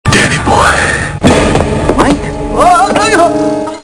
The Predator startles Danny with, "Danny Boy ..."